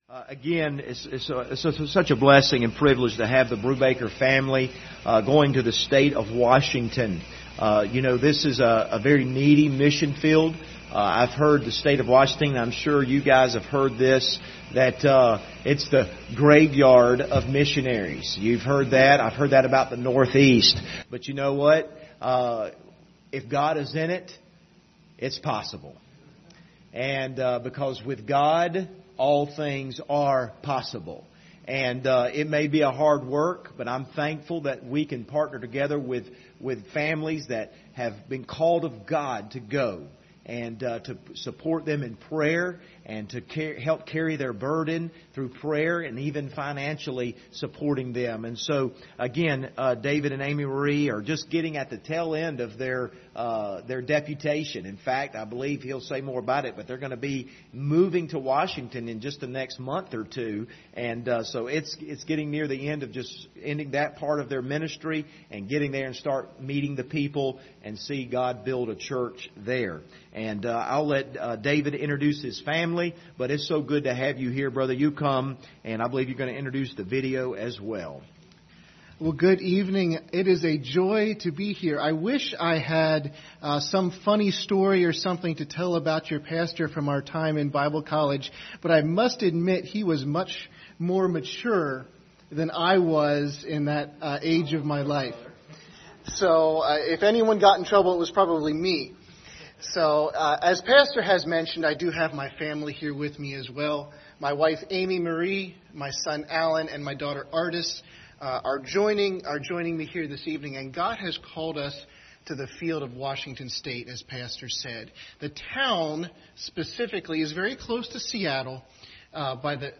General Service Type: Sunday Evening View the video on Facebook Topics: missions